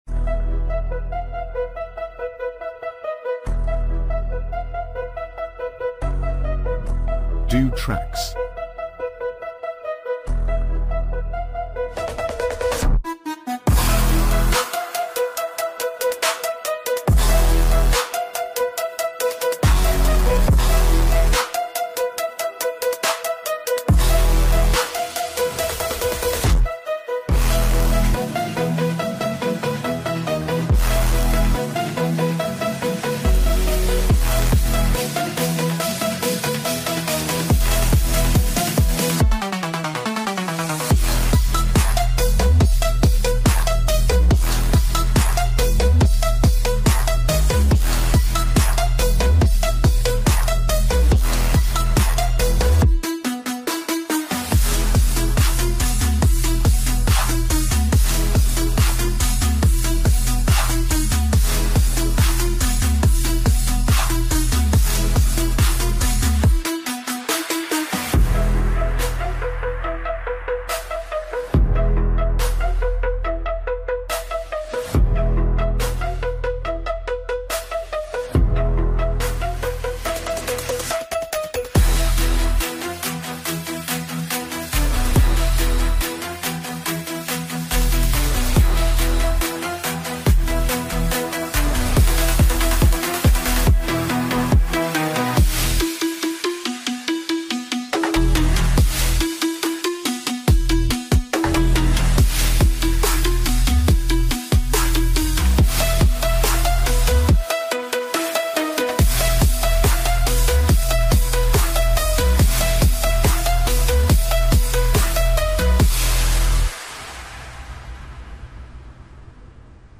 Royalty-Free Hip Hop Beat
epic no copyright music beat